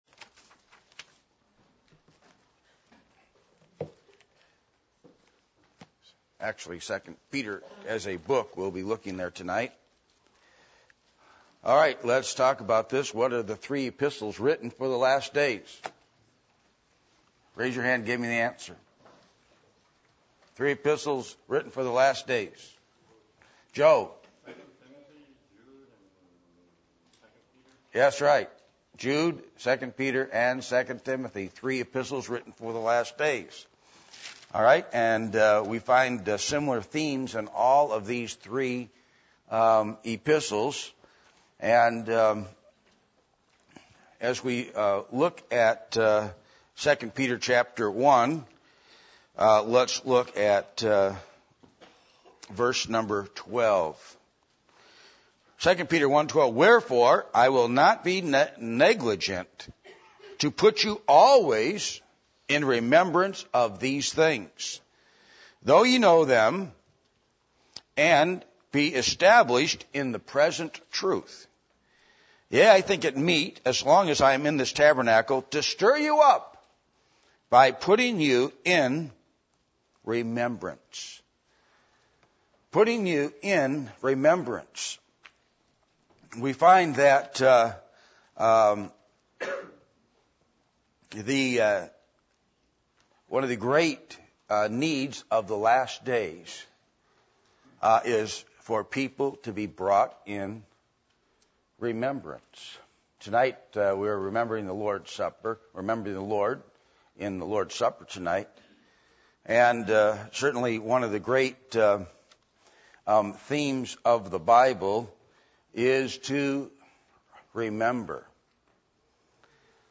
Passage: 2 Peter 1:12-13 Service Type: Sunday Evening